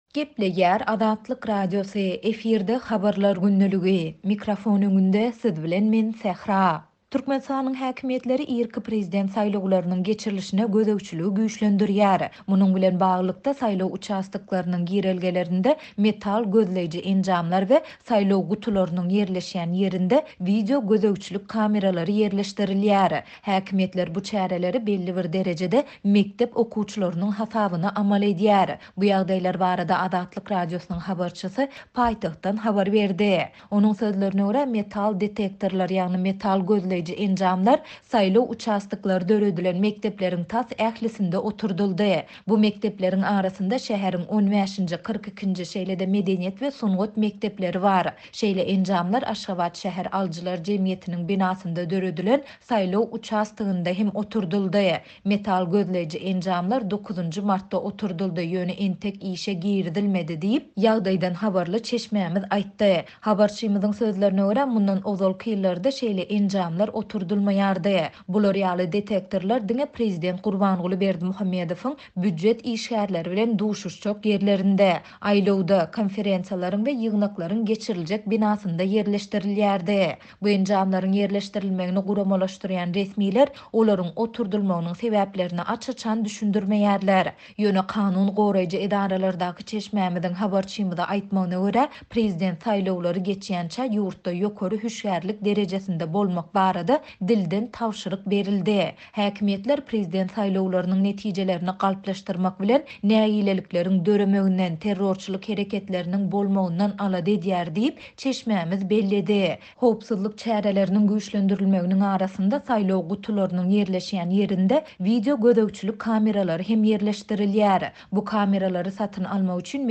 Bu ýagdaýlar barada Azatlyk Radiosynyň habarçysy paýtagtdan habar berýär.